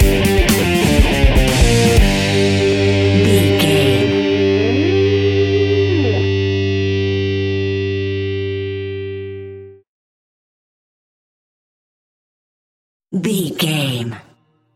Aeolian/Minor
hard rock
blues rock
distortion
Rock Bass
Rock Drums
heavy drums
distorted guitars
hammond organ